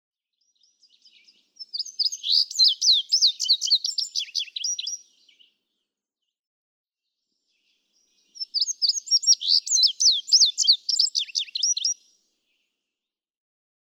Example 2. Indigo bunting: One song from each of two neighboring indigo buntings with similar songs (♫201).
Tye River Gap, Blue Ridge Parkway, Virginia.
♫201—one song from each of two neighboring males
201_Indigo_Bunting.mp3